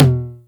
909 L Tom 1.wav